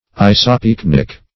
Isopycnic \I`so*pyc"nic\, n. (Physics)